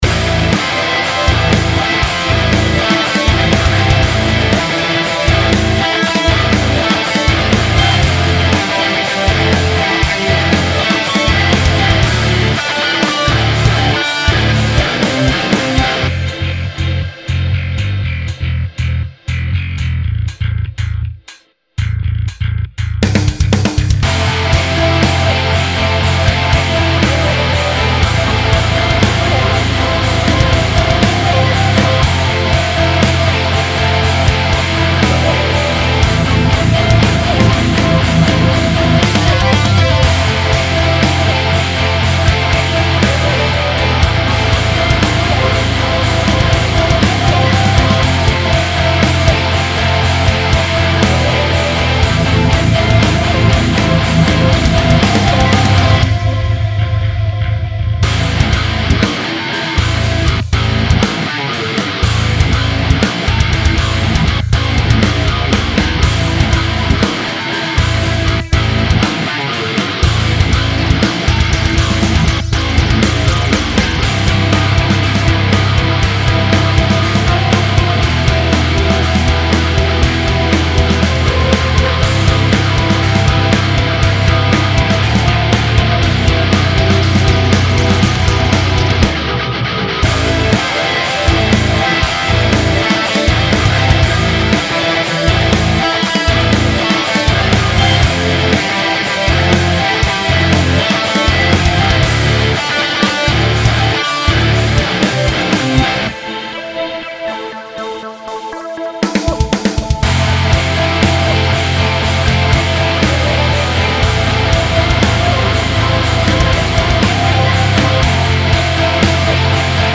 Wir sind eine moderne Metalcore-Band aus Bern und suchen für zukünftige Projekte eine/n Lead-Gitarrist/in.
Die Band besteht in dieser Konstellation seit 2025 und setzt sich aus Drums, Bass, Rhythm Guitar, Scream Vocals und Clean Vocals zusammen.
Wir schreiben eigene Songs unter einem Horror, 80’/90′, viel synth und einem theatralischem vibe.